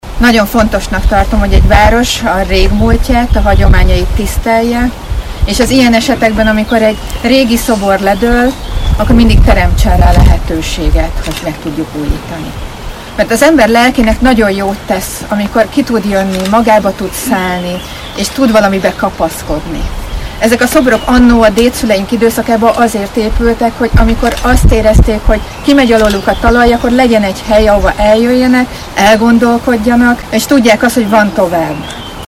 A szoboravatón először Heringes Anita polgármester szólt az ünneplőkhöz.